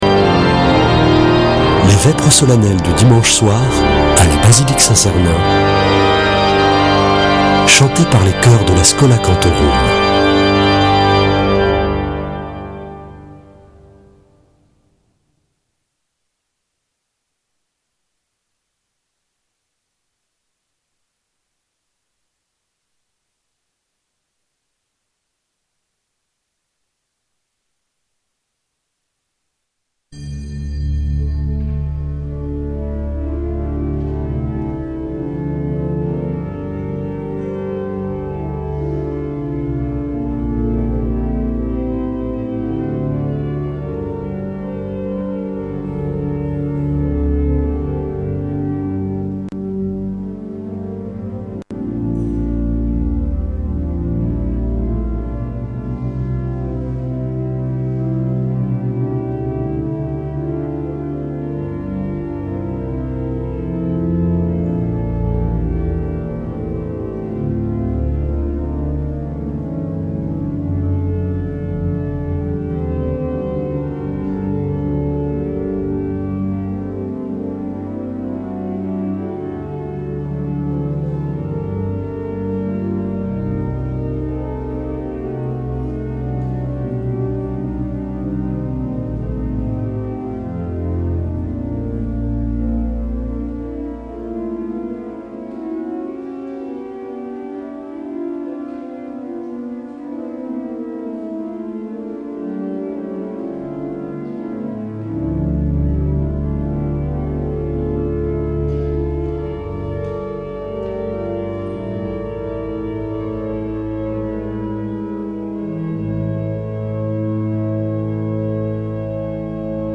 Vêpres de Saint Sernin du 12 janv.
Une émission présentée par Schola Saint Sernin Chanteurs